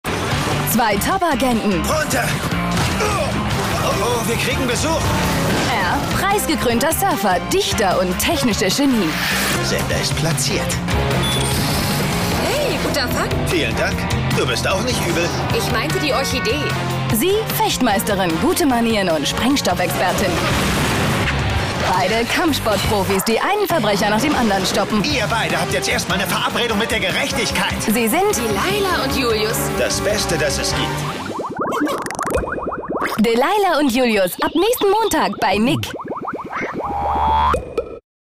Von sanft bis schrill, MTV bis Al Jazeera, von Kinderliedern bis zu Viva-Jingles
Sprechprobe: Werbung (Muttersprache):
german / english female voice over artist